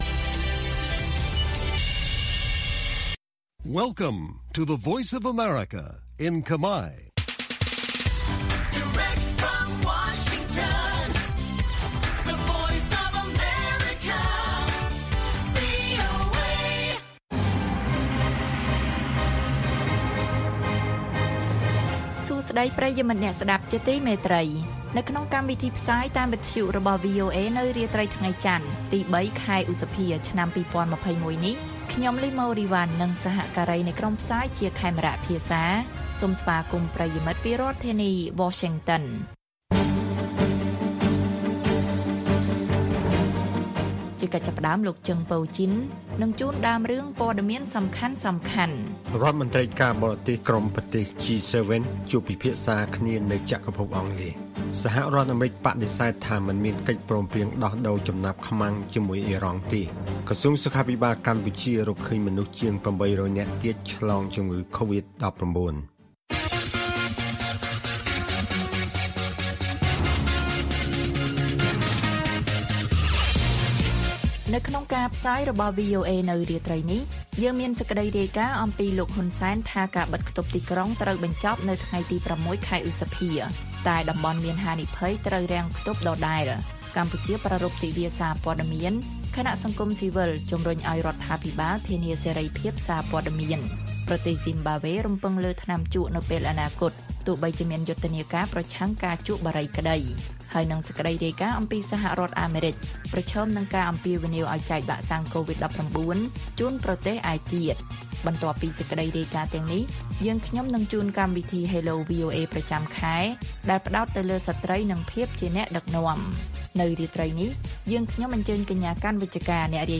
ព័ត៌មានពេលរាត្រី៖ ៣ ឧសភា ២០២១